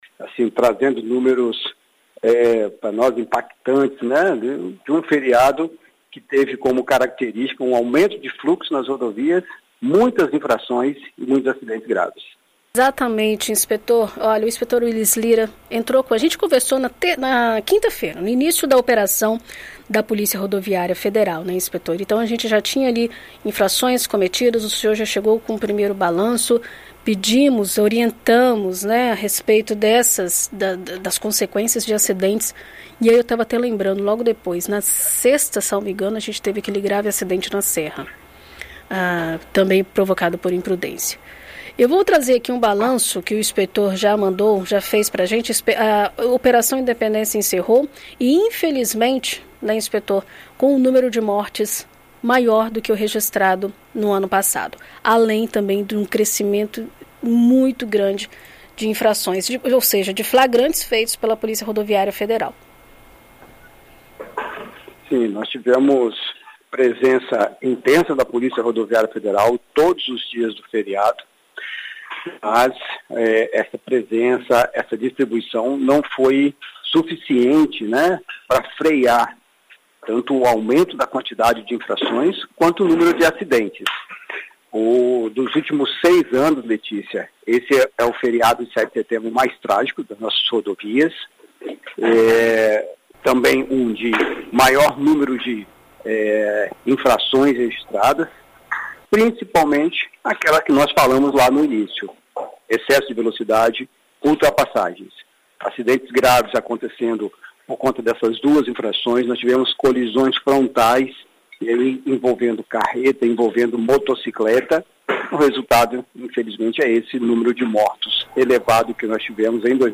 Em entrevista a BandNews FM ES nesta segunda-feira